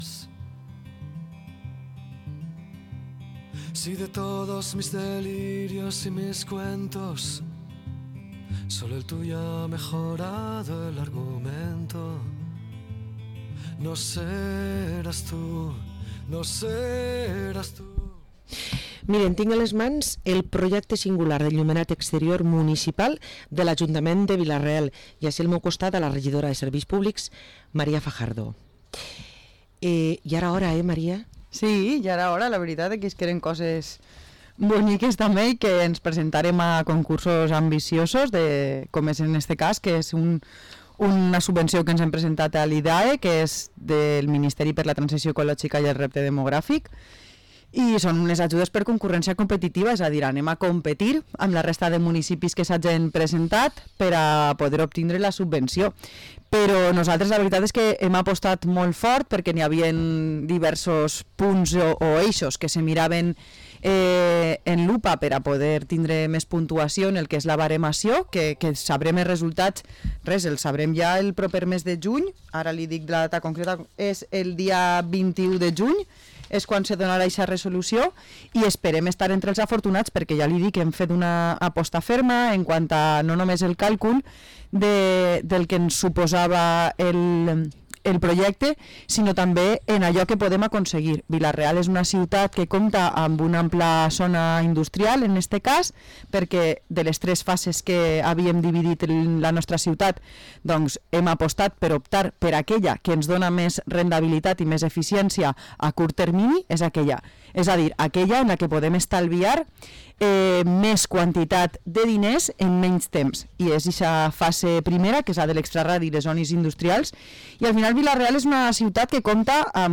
Parlem amb María Fajardo, regidora a l´Ajuntament de Vila-real